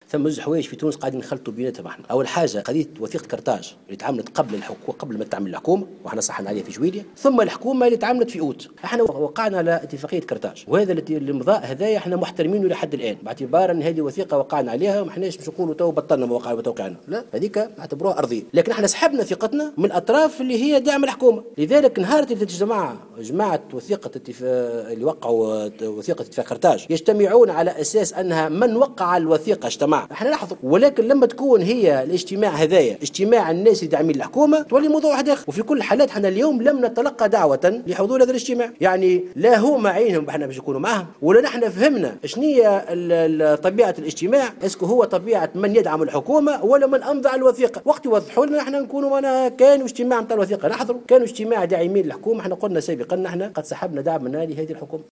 أكد الأمين العام لحركة مشروع تونس محسن مرزوق في تصريح لمراسل الجوهرة اف ام، عدم دعوتهم من طرف رئاسة الحكومة لحضور اجتماع الأطراف الموقعة على وثيقة قرطاج اليوم بقصر الضيافة.